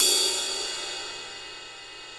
RIDE16.wav